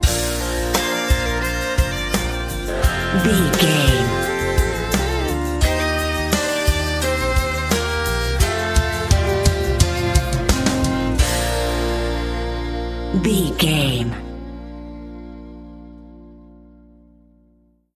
Ionian/Major
A♭
acoustic guitar
electric guitar
drums
violin
Pop Country
country rock
bluegrass
happy
uplifting
driving
high energy